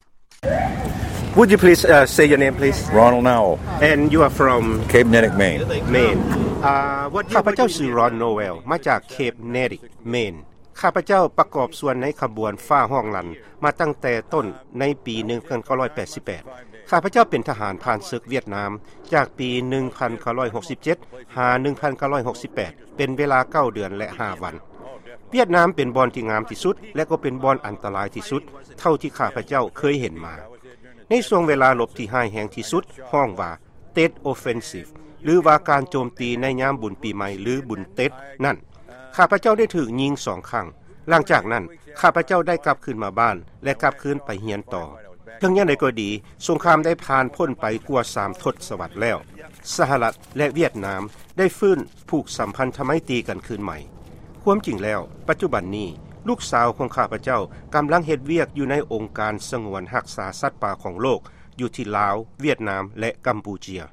ຟັງການສໍາພາດ ທະຫານຜ່ານເສິກ